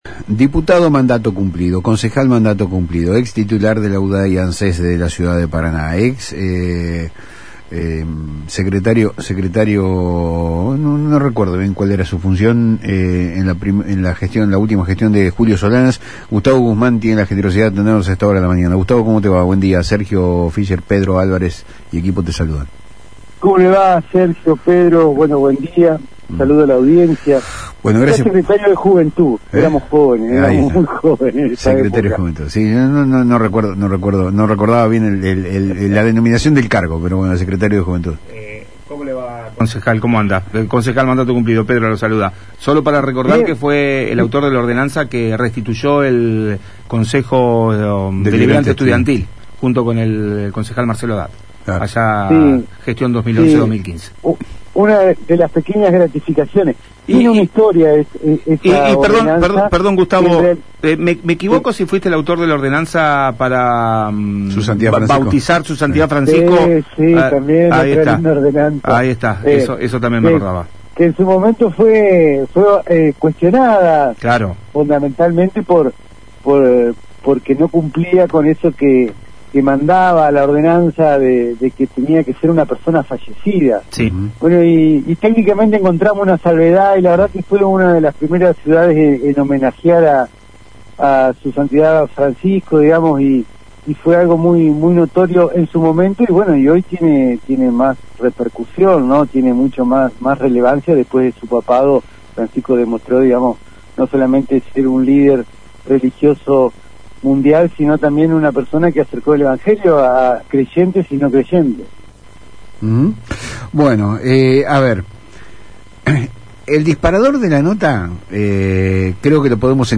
En una entrevista con Palabras Cruzadas por FM Litoral, Guzmán, un dirigente con una trayectoria marcada por su paso por el Concejo Deliberante de Paraná y el Congreso, no dudó en señalar las debilidades actuales de los partidos tradicionales.